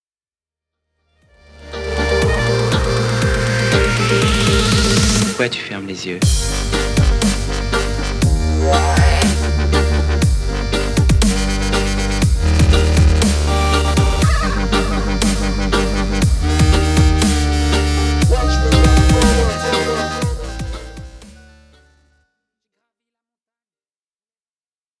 puissantes vibrations dubstep
Le reggae reste néanmoins le fil conducteur de cet EP.